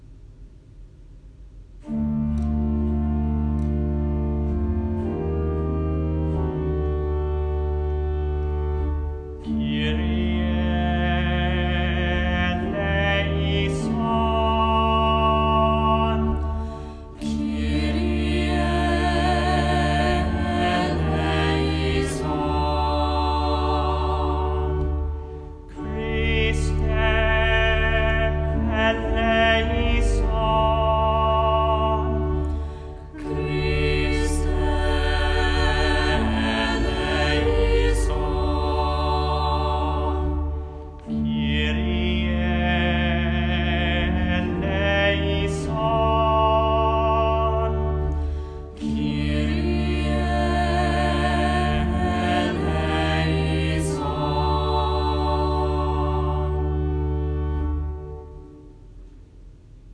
Schola 4-part (David Mass recordings)
choir-kyrie.m4a